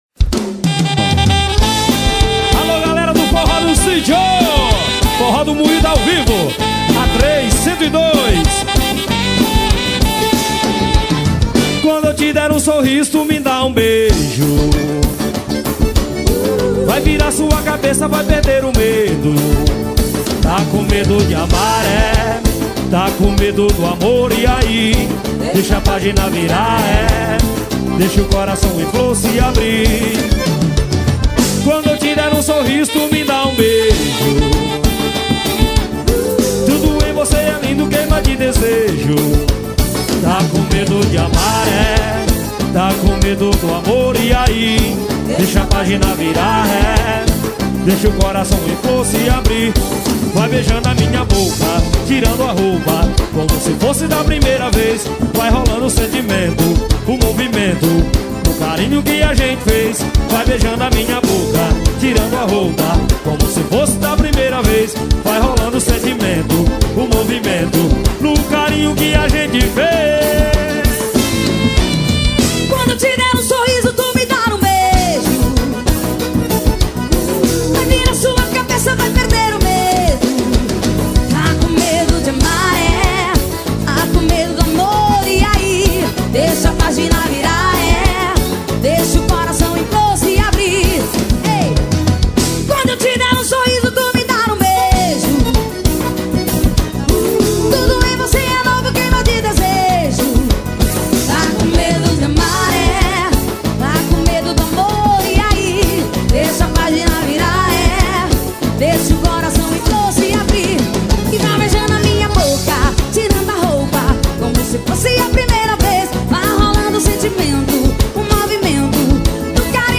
2024-12-19 11:51:16 Gênero: Forró Views